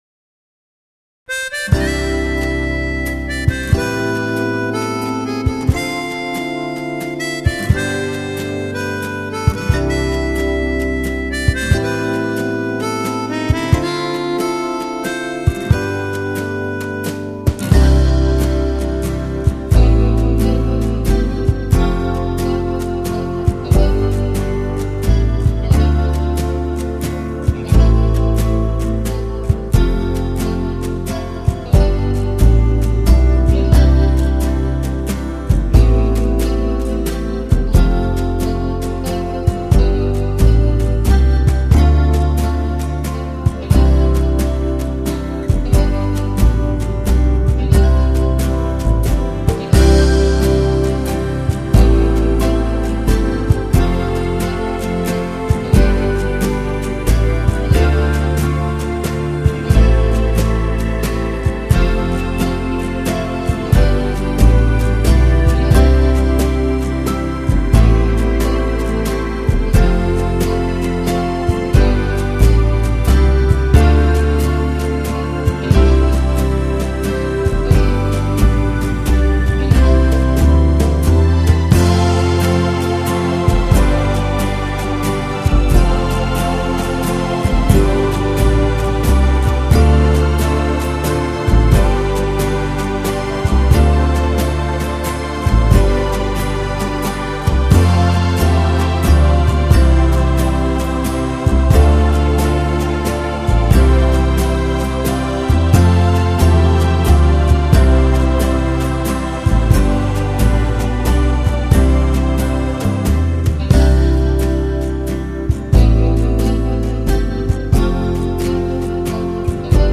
Genere: Valzer lento